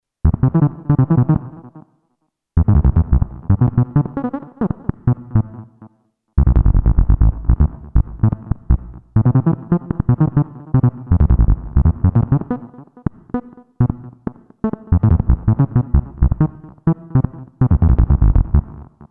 NPC Speech Sounds
Each NPC will have their own unique sounding voice pre-generated using synthesizers.
NOTE: These examples use heavy delay/echo which will not feature on the actual speech sounds.